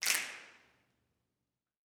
SNAPS 24.wav